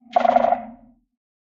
sculk_clicking2.ogg